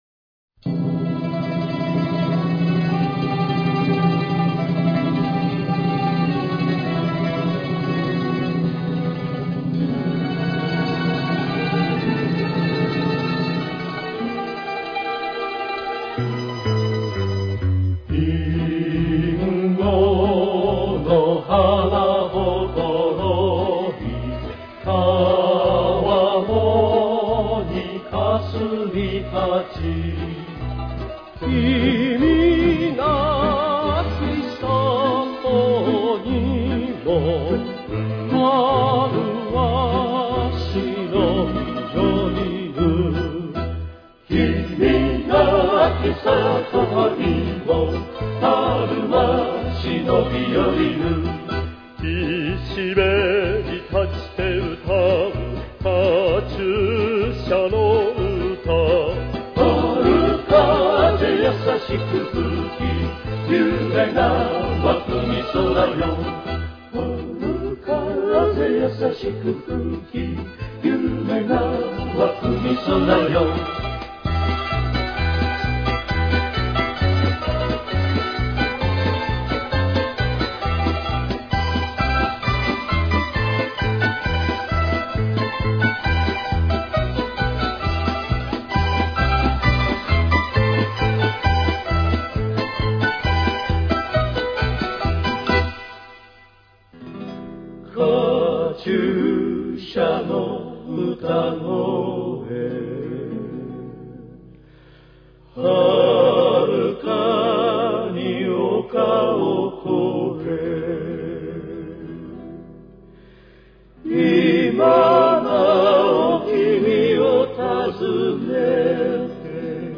Япония)Тональность: Ми минор. Темп: 132.